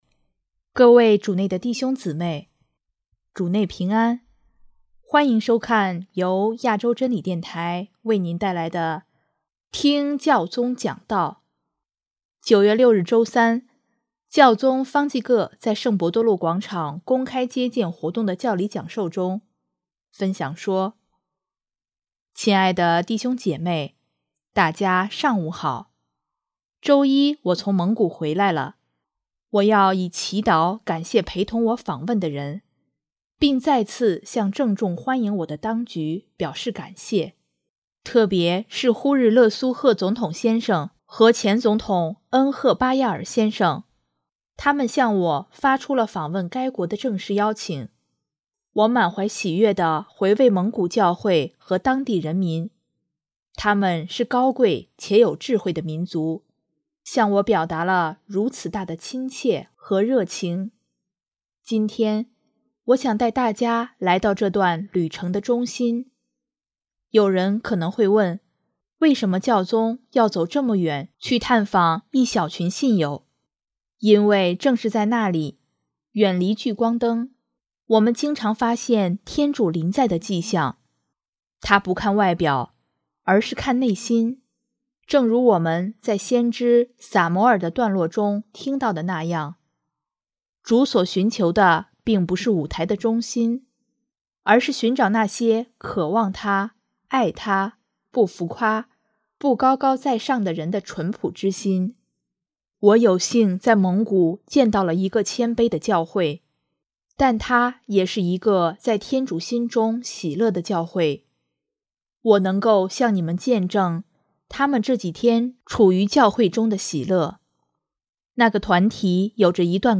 9月6日周三，教宗方济各在圣伯多禄广场公开接见活动的教理讲授中，分享说：